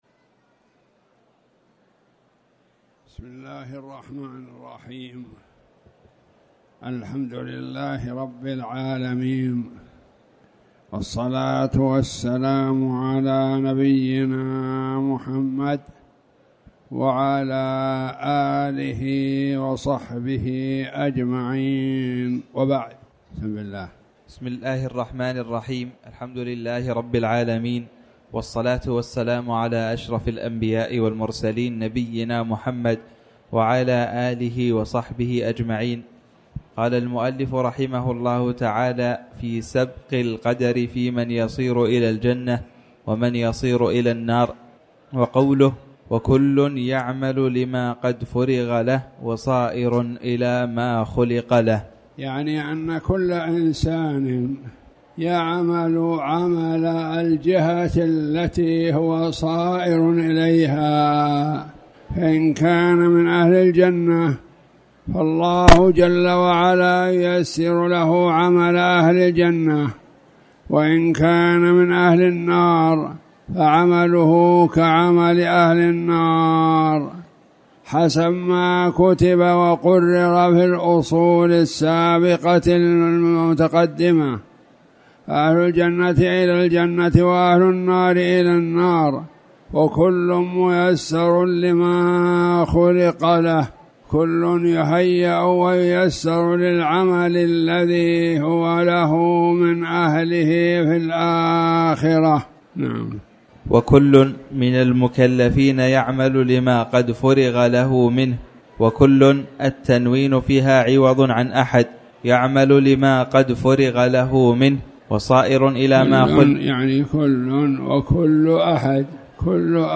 تاريخ النشر ١٨ صفر ١٤٣٩ هـ المكان: المسجد الحرام الشيخ